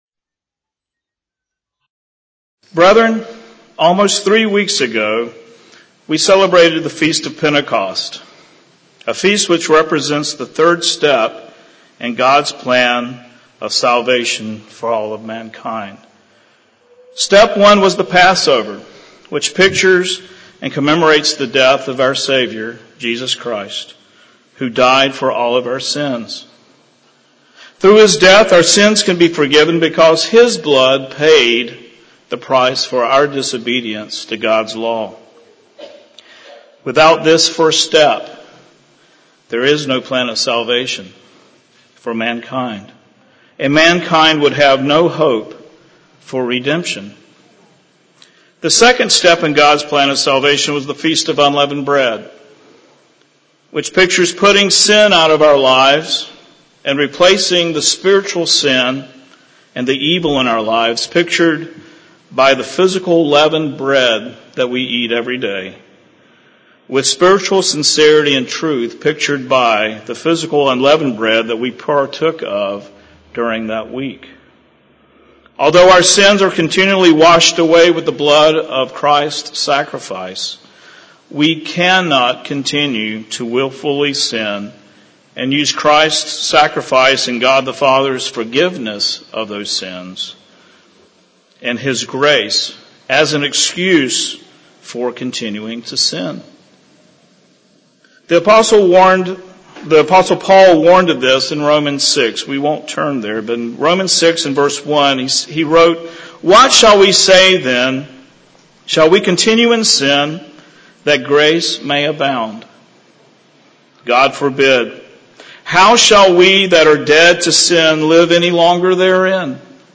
sermon
Given in Houston, TX